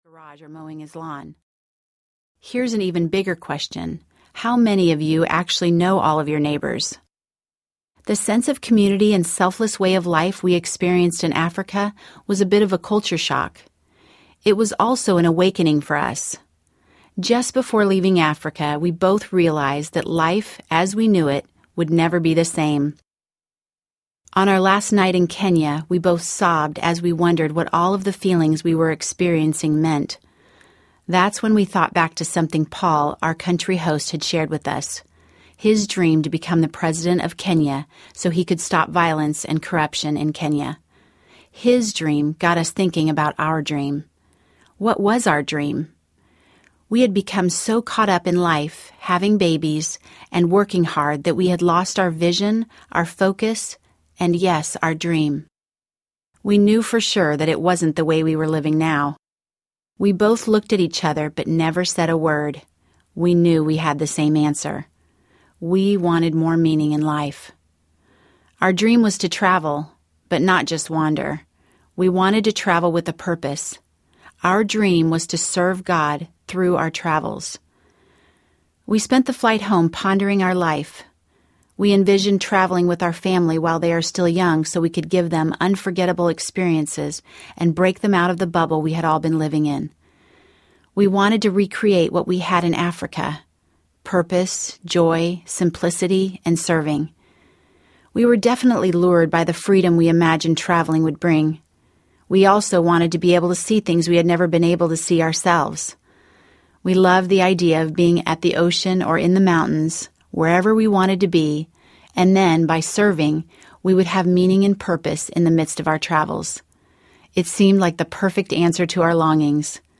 Passion to Action Audiobook
Narrator